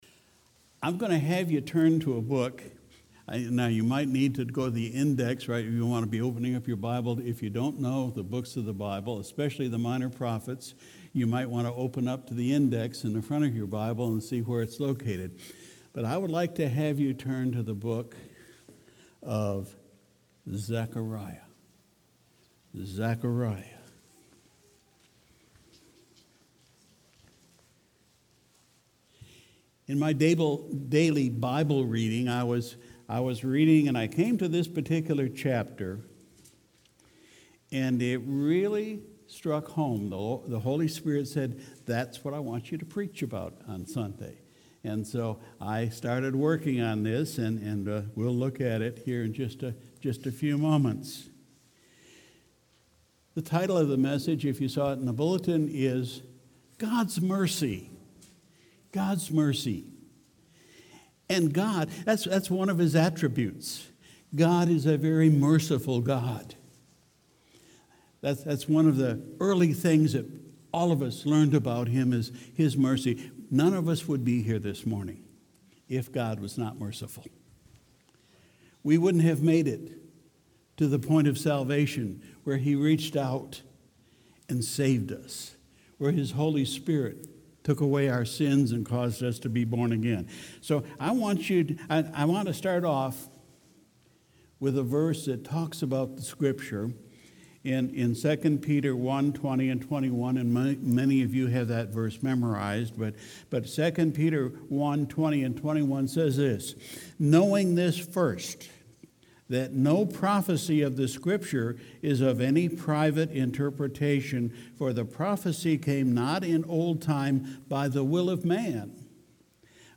Sunday, November 3, 2019 – Morning Service